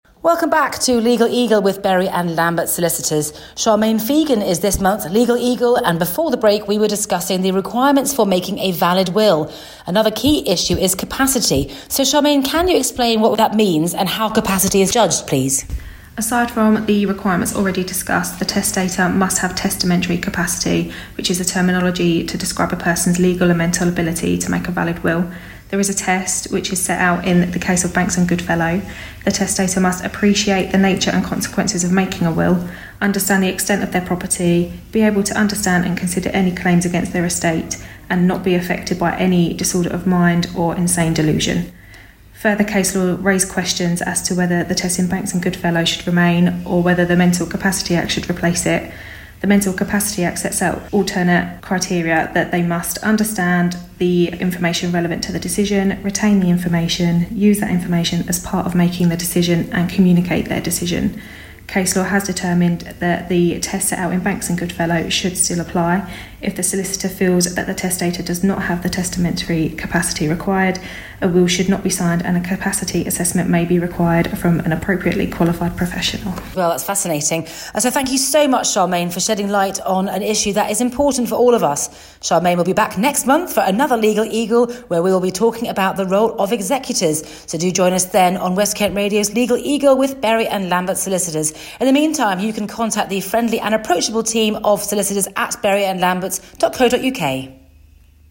More from INTERVIEW REPLAYS